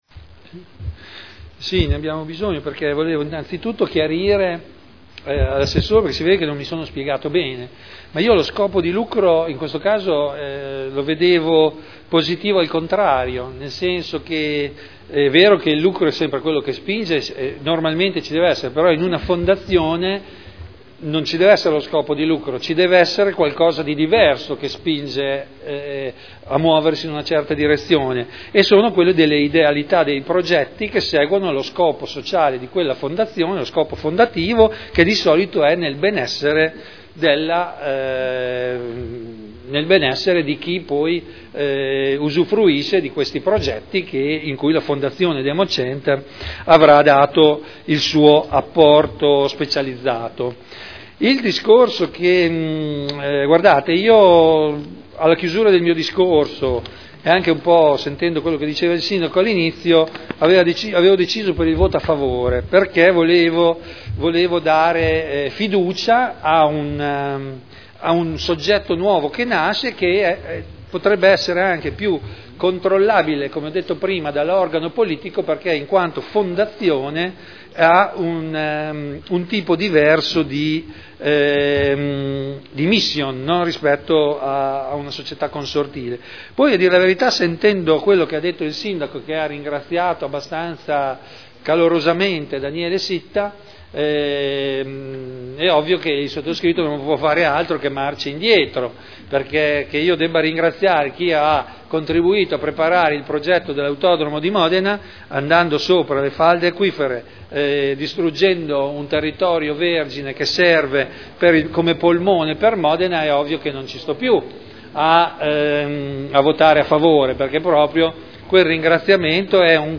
Vittorio Ballestrazzi — Sito Audio Consiglio Comunale
Dichiarazione di voto su proposta di deliberazione. Trasformazione di Democenter-Sipe da Società Consortile a Responsabilità Limitata a Fondazione – Approvazione dello statuto